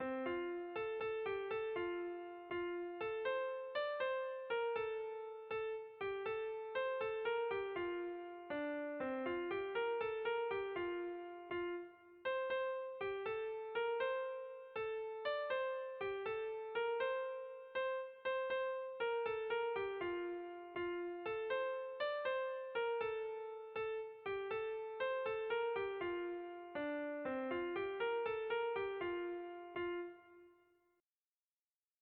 Tragikoa
A-B-C